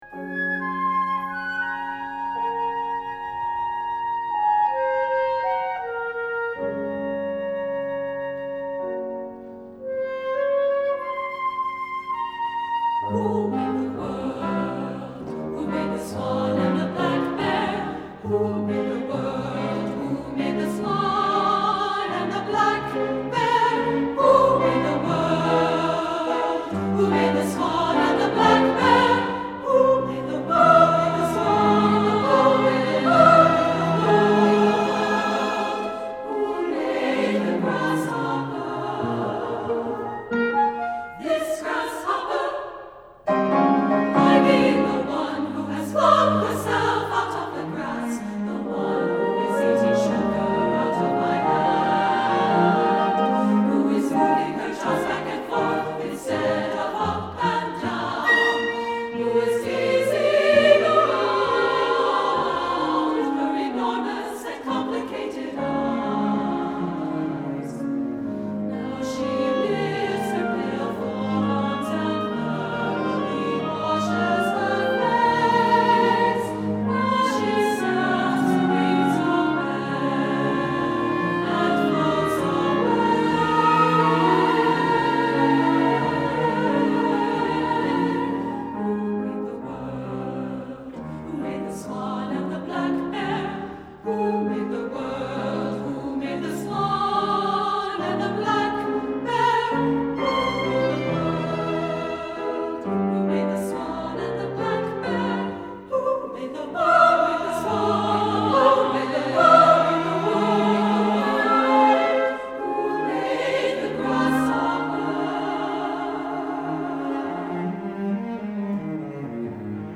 Moderate
SSA, piano, flute obligato 4:30 $2.50